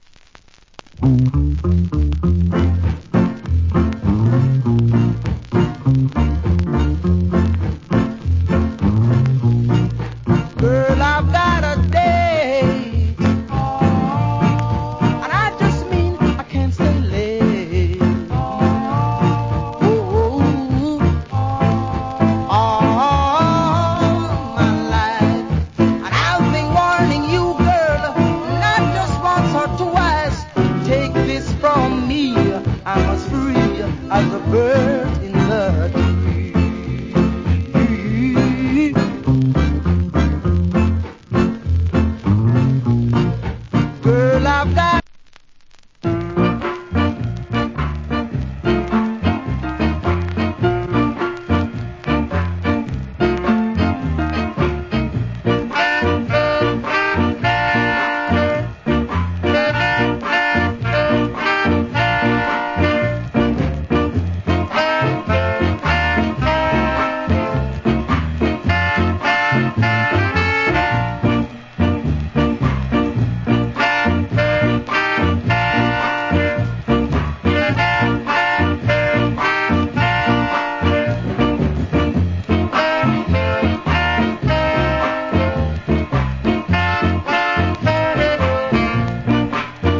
Cool Rock Steady Inst.